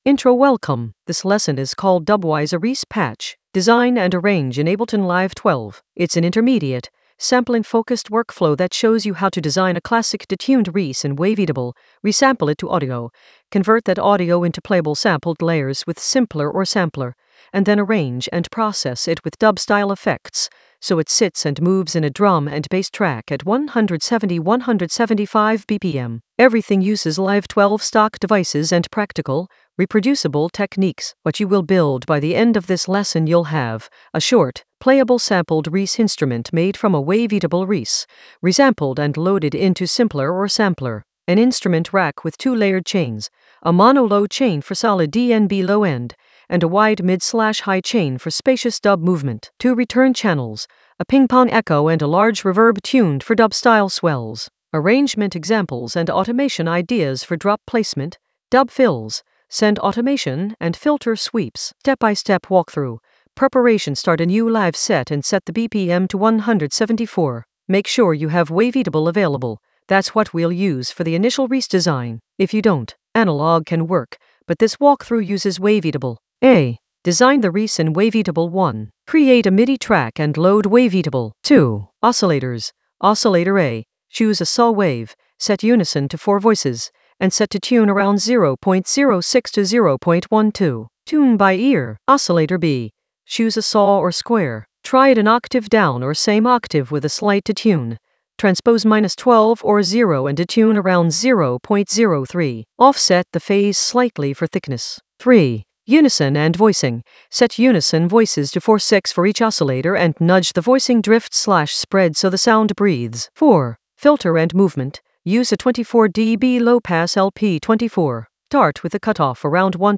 Narrated lesson audio
The voice track includes the tutorial plus extra teacher commentary.
dubwise-a-reese-patch-design-and-arrange-in-ableton-live-12-intermediate-sampling.mp3